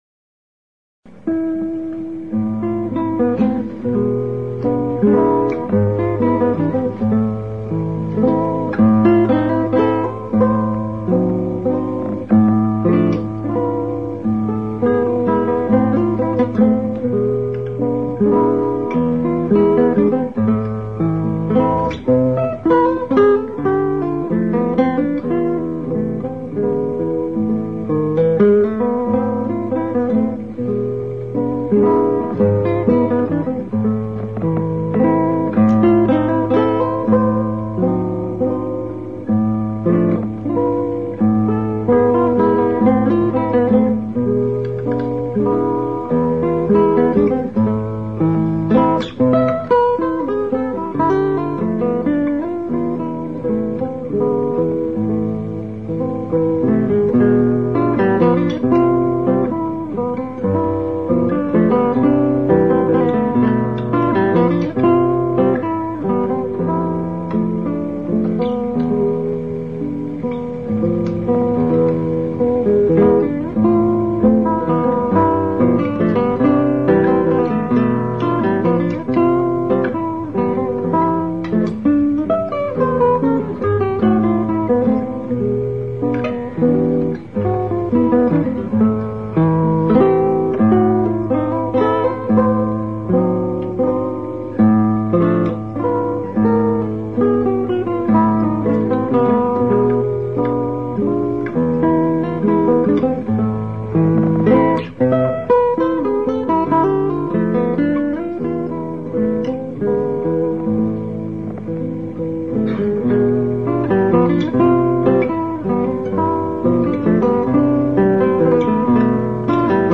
Waltz F. Chopin
Classical Guitar Kresge Little Theater MIT Cambridge, Massachusetts USA March 22